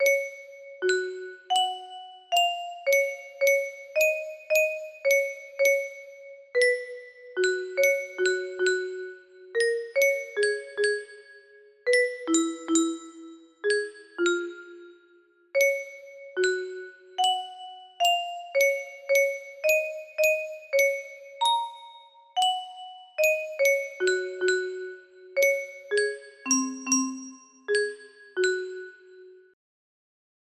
Voyager 1 music box melody